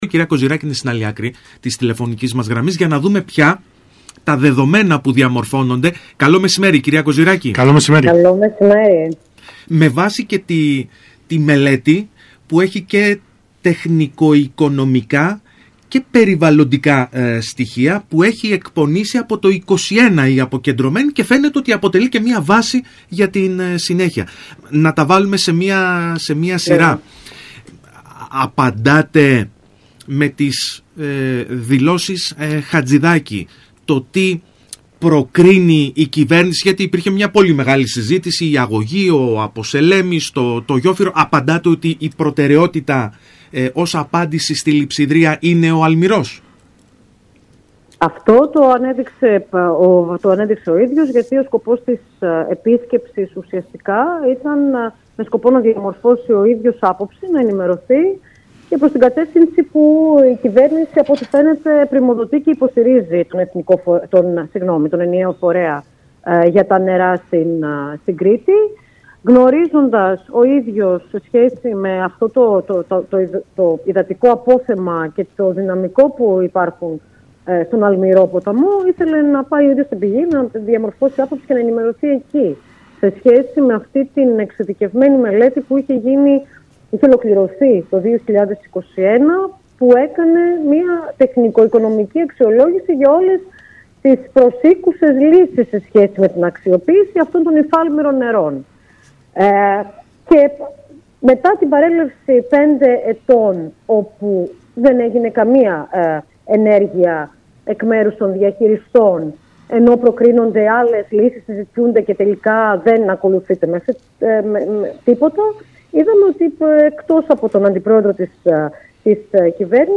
Ακούστε εδώ όσα είπε στον ΣΚΑΙ Κρήτης 92.1 η Γραμματέας της Αποκεντρωμένης Διοίκησης Κρήτης Μαρία Κοζυράκη: